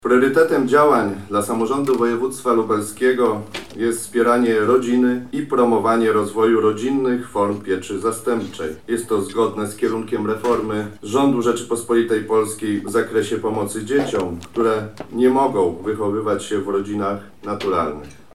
Konferencja miała na celu podkreślenie znaczenia wpływu wczesnych doświadczeń emocjonalnych na rozwój dziecka. O istocie działań ROPS mówił wicemarszałek Marek Wojciechowski: